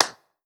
Clap (Monster).wav